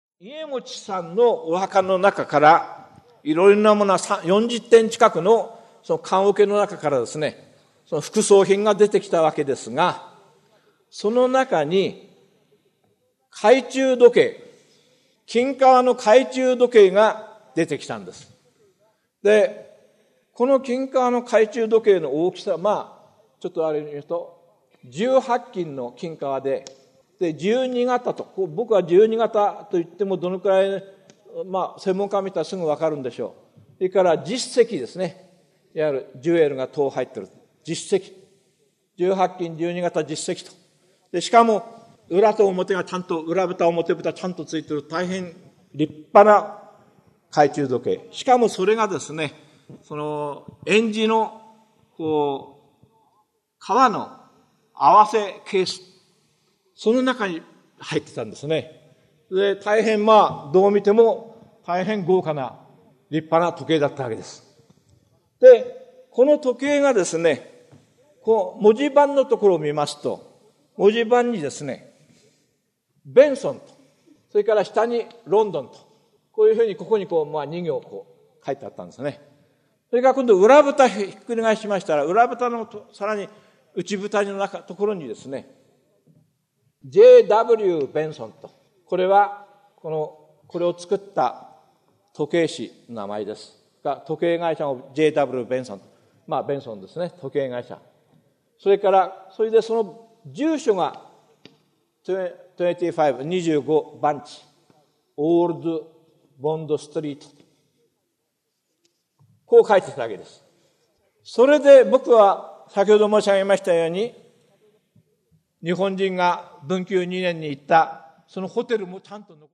名だたる文筆家が登場する、文藝春秋の文化講演会。
（1989年10月6日 和歌山市民会館 菊池寛生誕記念百周年記念講演会より）